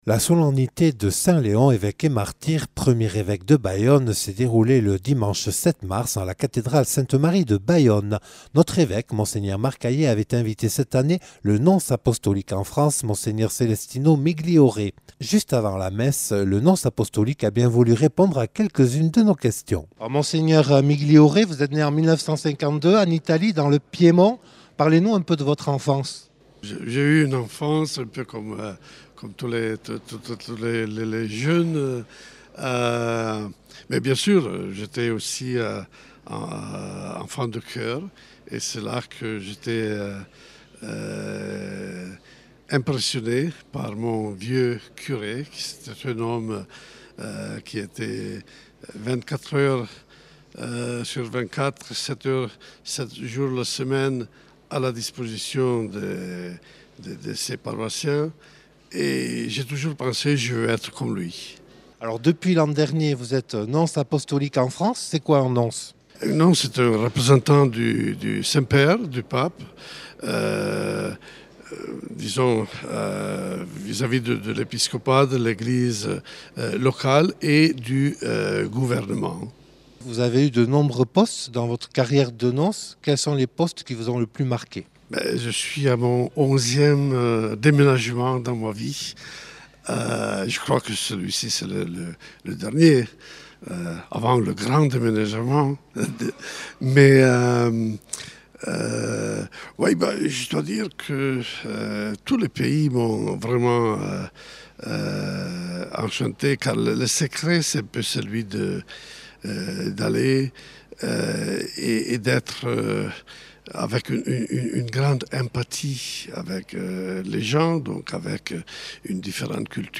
Reportage consacré à la messe de la Saint-Léon présidée par Mgr Celestino Migliore, nonce apostolique en France.